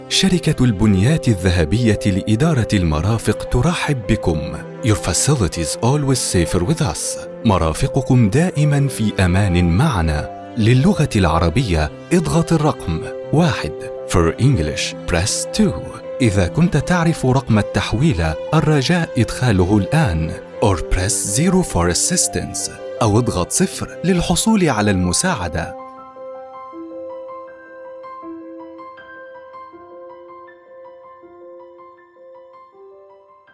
رد آلي IVR شركة البنيات الذهبية السعودية
رد آلي (IVR) بصوت واضح ورسمي يناسب أنظمة خدمة العملاء والبدالات الهاتفية قدّمت في هذه العينة أداءً احترافيًا بجودة استوديو، بنبرة هادئة، ثابتة، وسهلة الفهم، مما يجعلها مثالية للشركات، البنوك، العيادات، المطاعم، والمنشآت التي تحتاج إلى نظام رد آلي يعتمد عليه التسجيل يتم بجودة عالية ونطق فصيح مفهوم، مع مراعاة الإيقاع المناسب لتوجيه العميل داخل القوائم والرسائل الصوتية هذا الأسلوب مخصص للرسائل الترحيبية، رسائل الانتظار، القوائم الصوتية، والتنبيهات الهاتفية الخاصة بالشركات والهيئات إذا كنتم تبحثون عن صوت محترف للرد الآلي أو بدالة هاتفية متقنة فهذه العينة تُمثّل جودة الأداء الذي أقدّمه